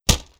Close Combat Attack Sound 1.wav